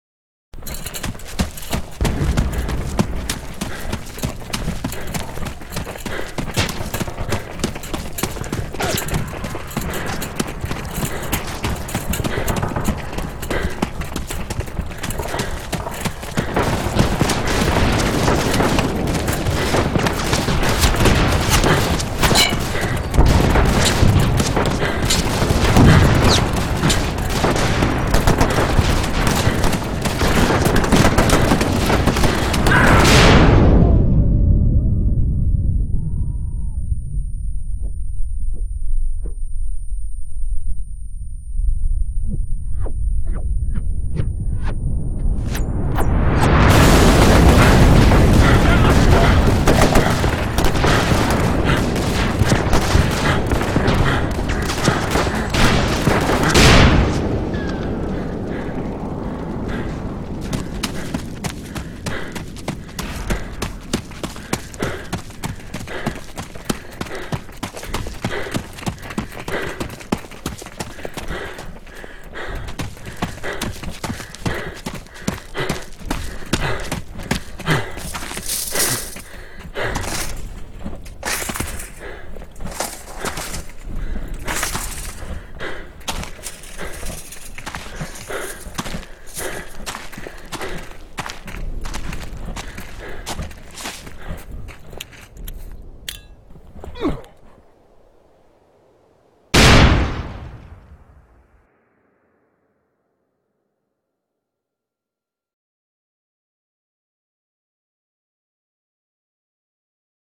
На этой странице собраны звуки войны и перестрелок — тревожные, резкие, напоминающие о разрушениях.
Звуки стрельбы, крики солдата и грохот взрывов на поле боя